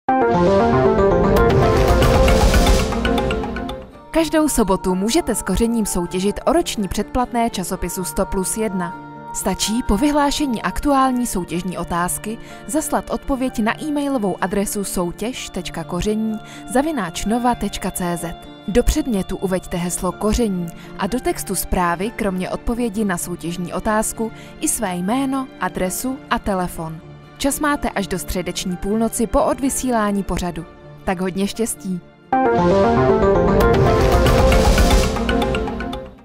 Profesionální český ženský voiceover
profesionální zvuk a vysoká technická kvalita